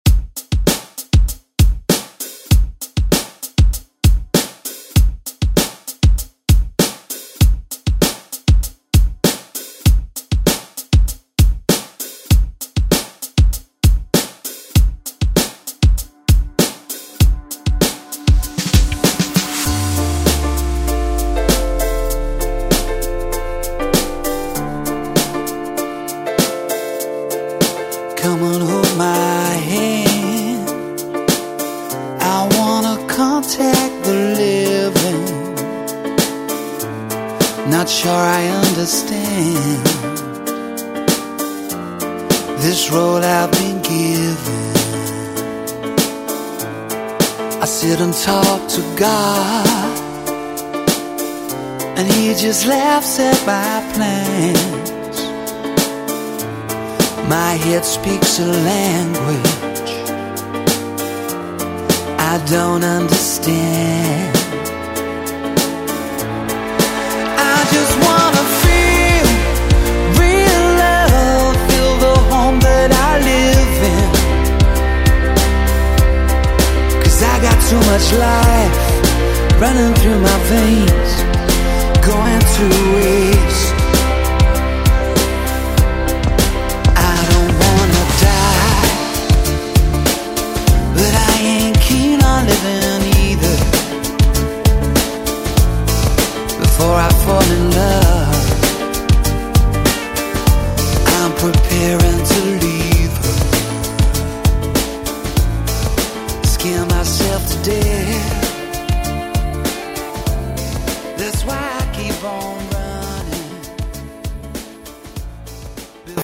Electronic Pop Rock Music
Extended ReDrum Clean 98 bpm